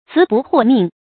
辭不獲命 注音： ㄘㄧˊ ㄅㄨˋ ㄏㄨㄛˋ ㄇㄧㄥˋ 讀音讀法： 意思解釋： 謂辭謝而未獲允許。